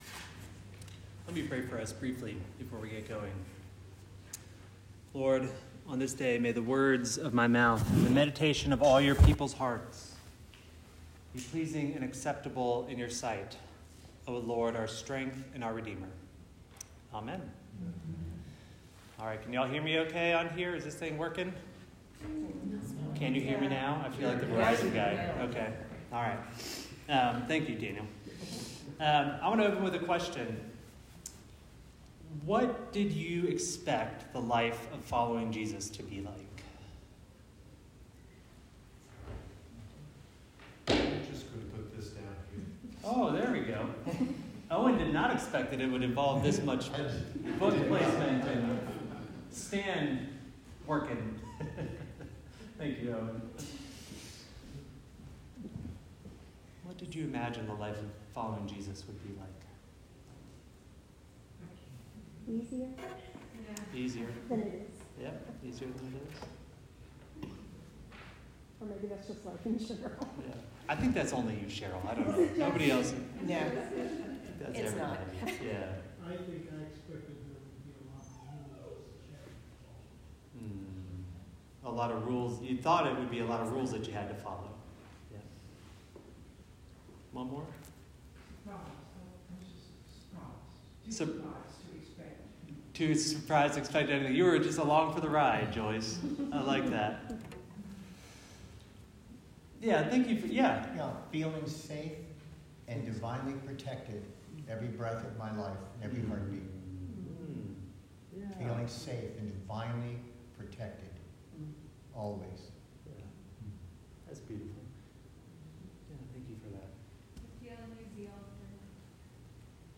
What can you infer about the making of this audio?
Maundy Thursday